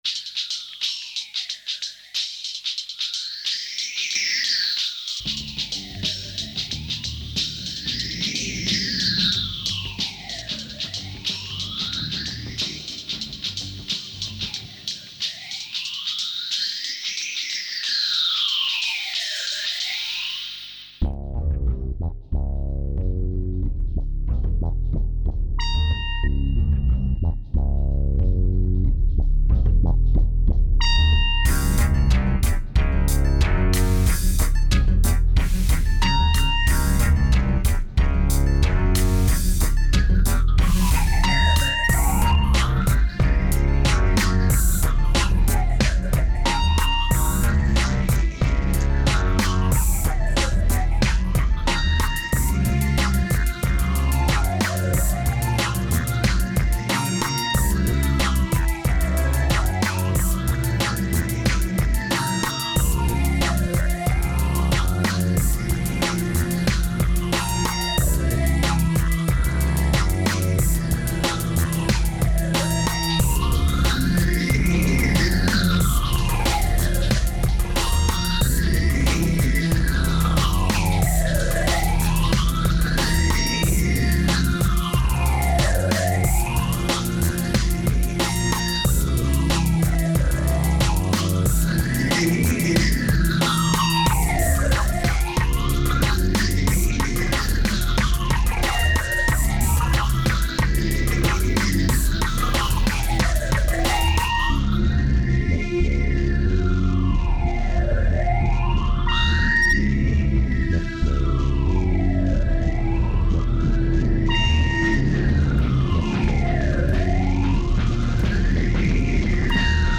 2217📈 - 12%🤔 - 92BPM🔊 - 2009-01-18📅 - -110🌟